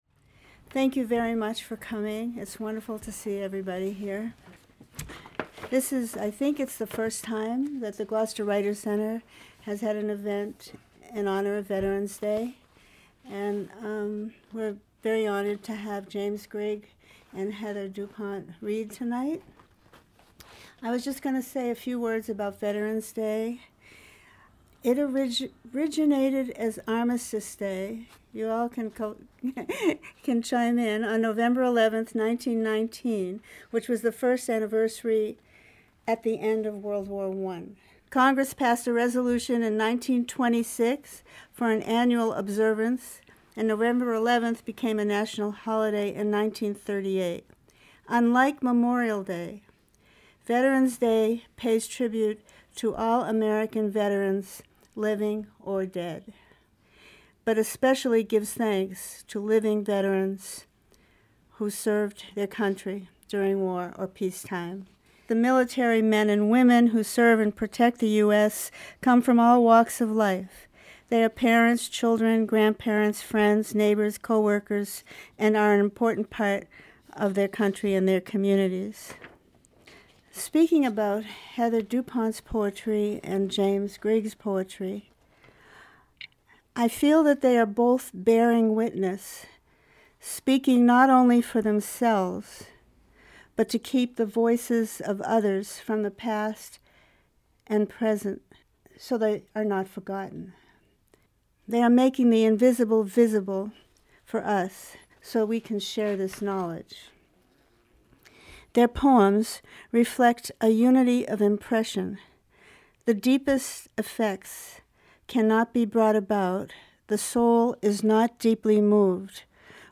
An evening to honor Veterans Day and those who have served.